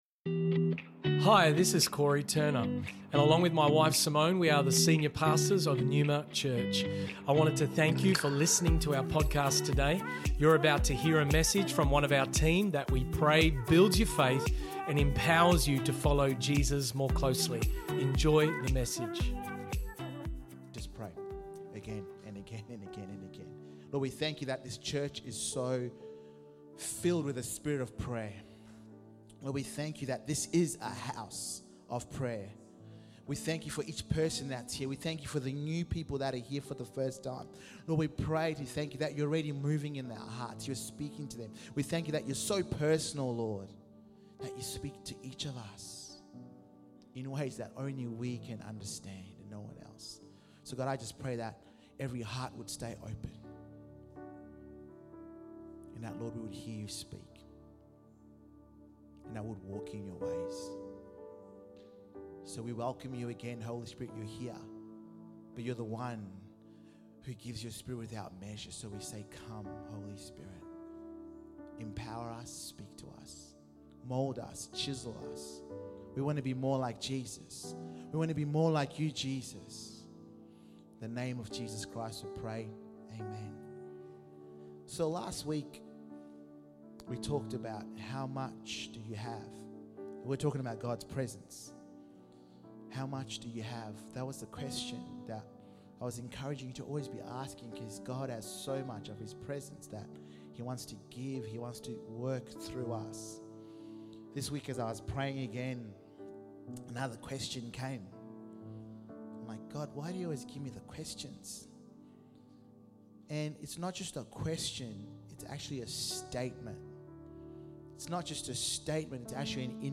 Neuma Church Melbourne South Originally Recorded at the 10AM Service on Sunday 26th November 2023 Note